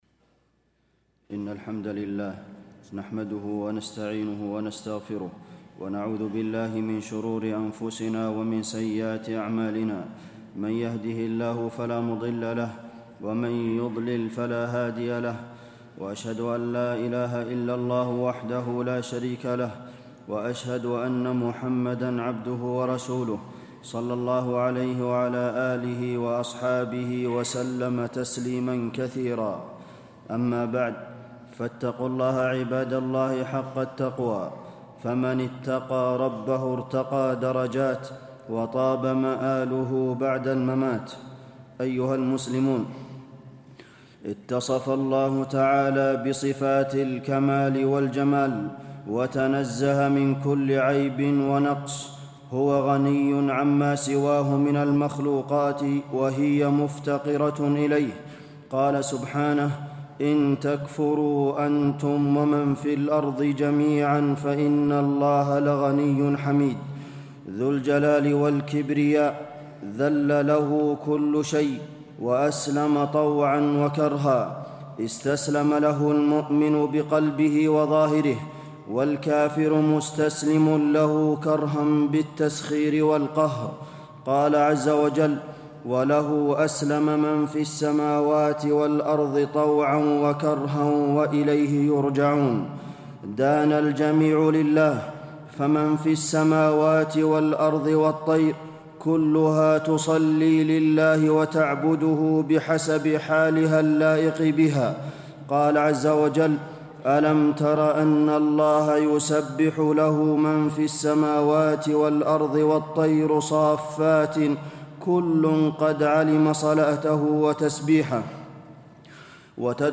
تاريخ النشر ٢٣ شوال ١٤٣٤ هـ المكان: المسجد النبوي الشيخ: فضيلة الشيخ د. عبدالمحسن بن محمد القاسم فضيلة الشيخ د. عبدالمحسن بن محمد القاسم عبودية الكائنات لله تعالى